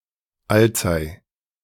Alzey (German pronunciation: [ˈaltsaɪ]
De-Alzey.ogg.mp3